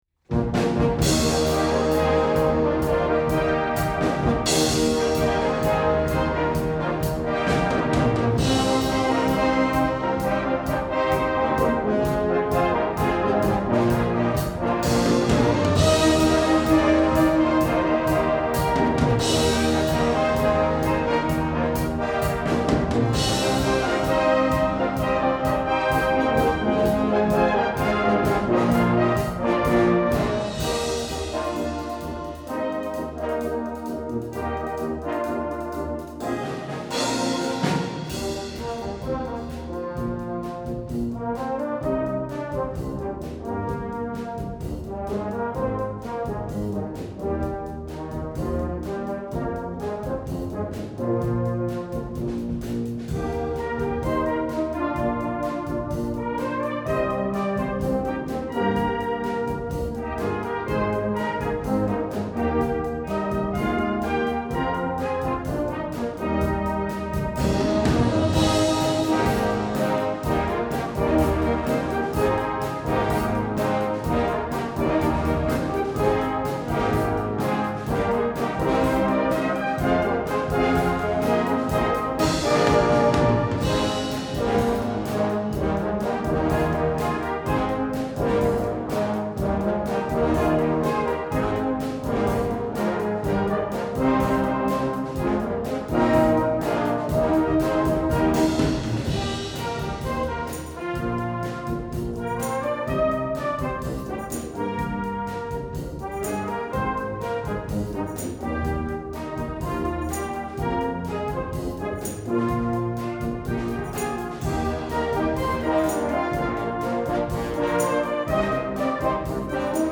Partitions pour orchestre d'harmonie, ou brass band.